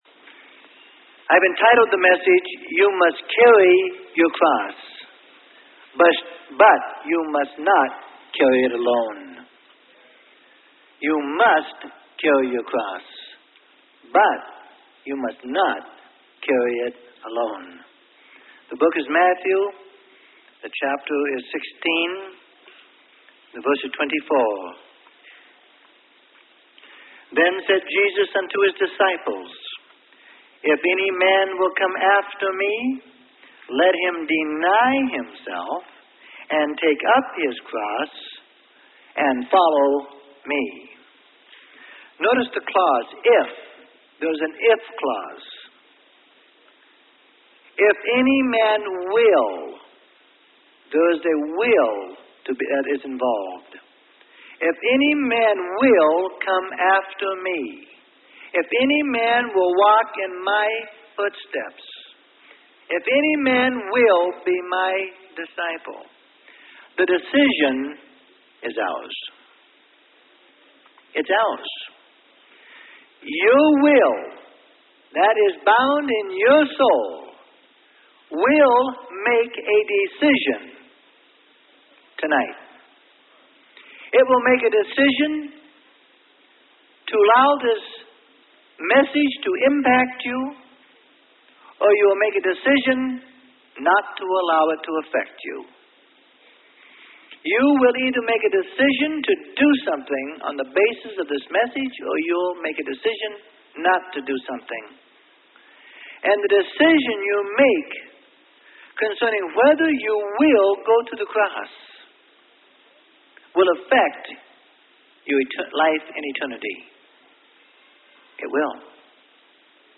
Sermon: You Must Carry Your Cross, But You Must Not Carry It Alone.